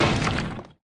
pickaxe.opus